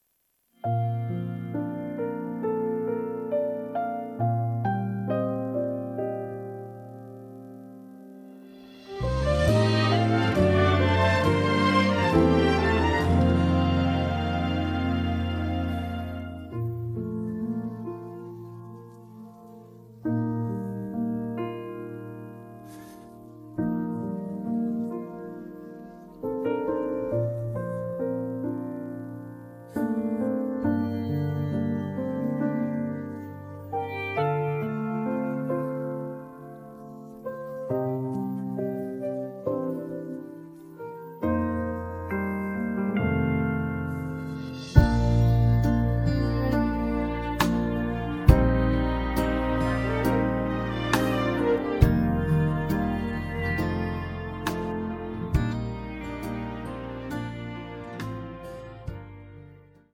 음정 -1키 3:45
장르 가요 구분 Voice Cut